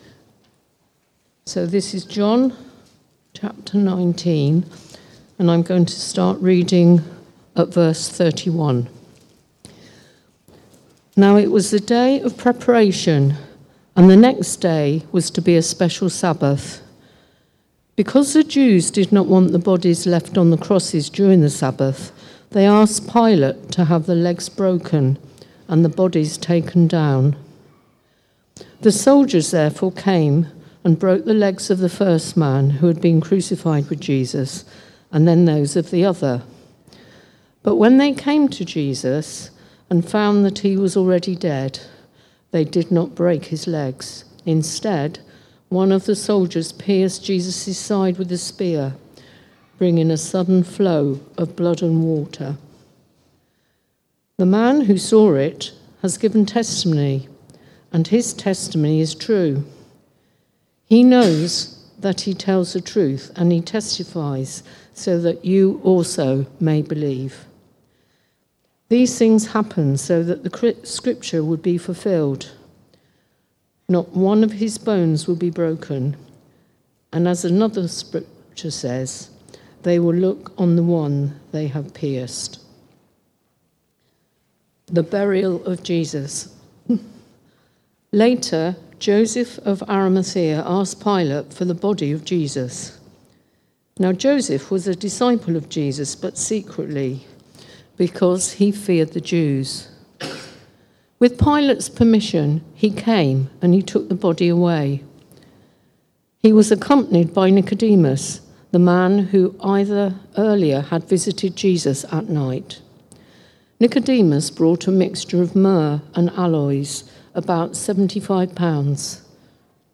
The Fulfillment (John 19:31-42) from the series Life From Death. Recorded at Woodstock Road Baptist Church on 29 March 2026.